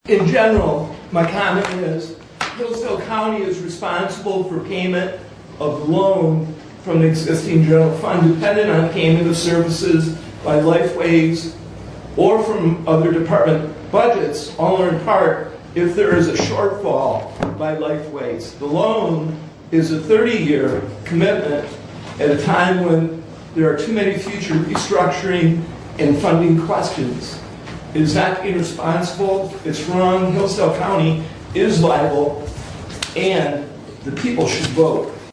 The audio featured below is Commissioner Ingles giving his thoughts on the matter, citing concerns with Hillsdale County being on the hook for the bond should LifeWays be unable to pay it back.